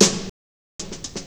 • '00s Big Snare One Shot G Key 168.wav
Royality free steel snare drum sample tuned to the G note. Loudest frequency: 3583Hz
00s-big-snare-one-shot-g-key-168-ufX.wav